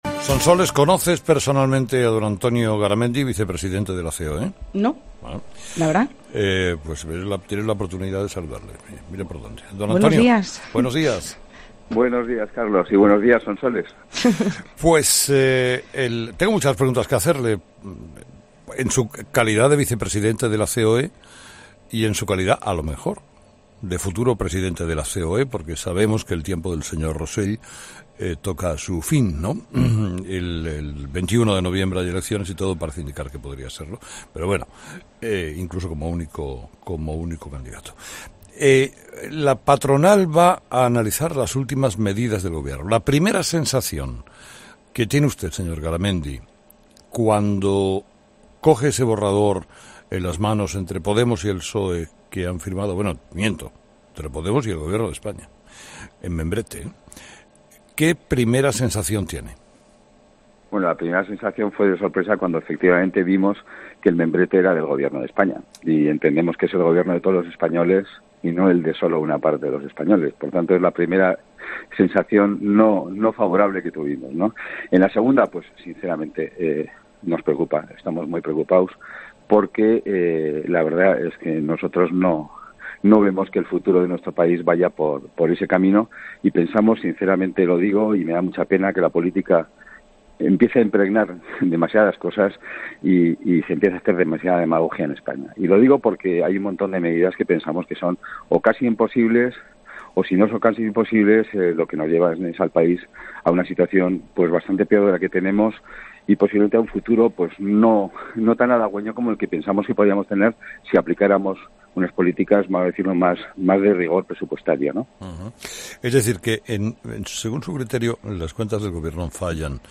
Antonio Garamendi, vicepresidente de la CEOE, ha sido entrevistado este lunes en 'Herrera en COPE' con motivo del borrador de Presupuestos Generales del Estado rubricado por el Gobierno y Podemos.